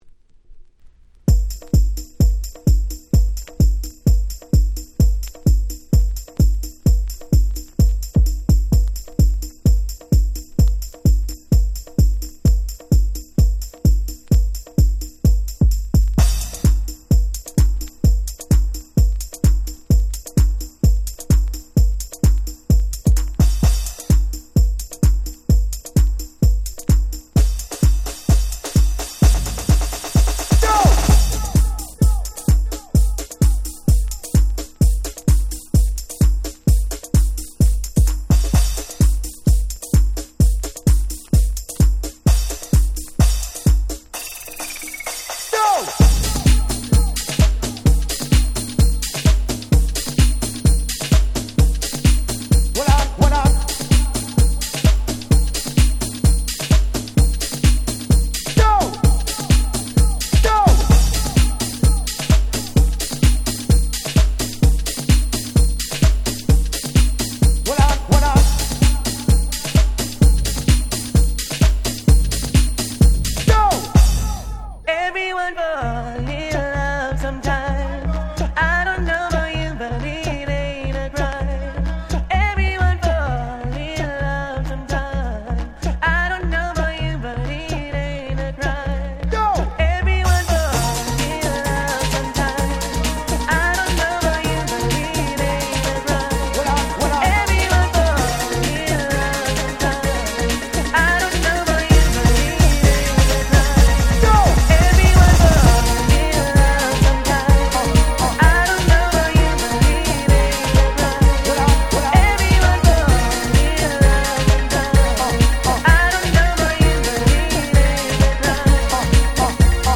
Nice Dancehall Reggaeコンピレーション！！